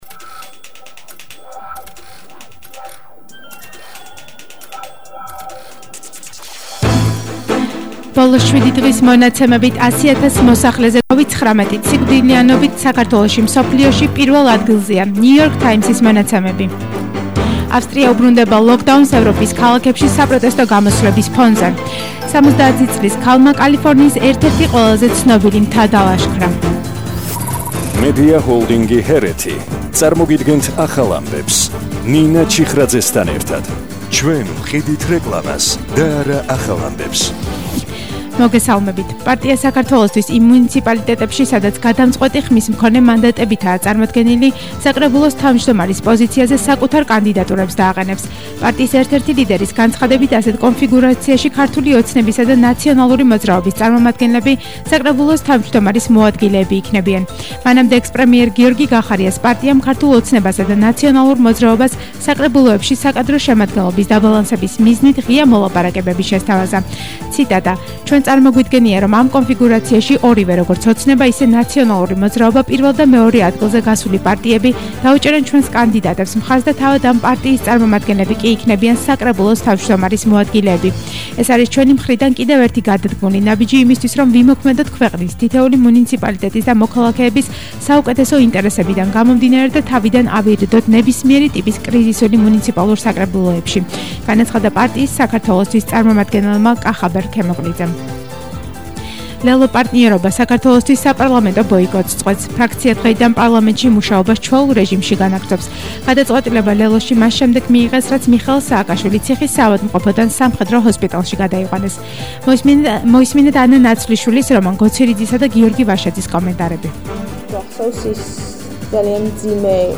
ახალი ამბები 16:00 საათზე –22/11/21 – HeretiFM